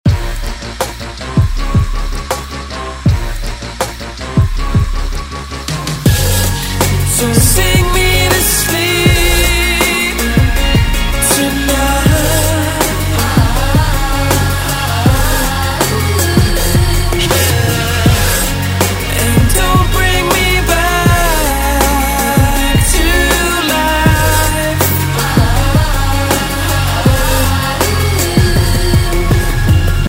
• Качество: 128, Stereo
britpop